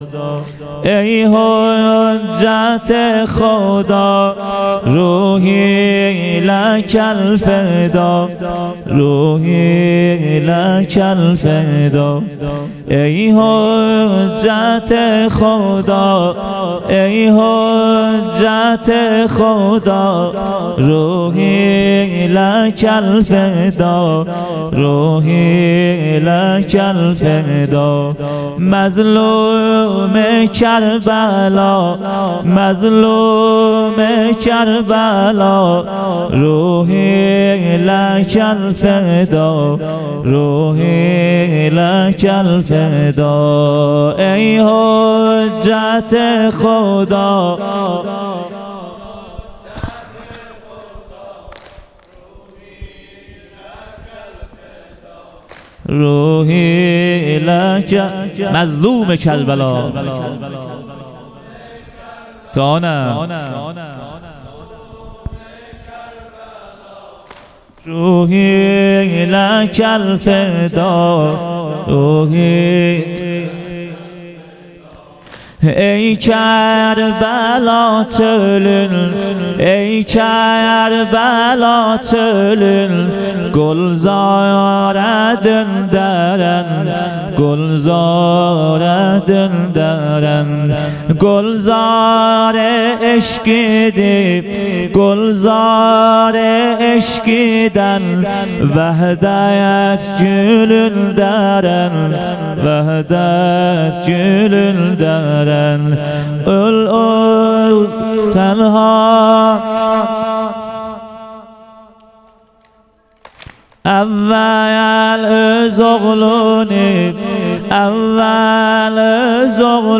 شب دوازدهم محرم 98 - بخش سوم سینه زنی (واحد)
محرم و صفر 98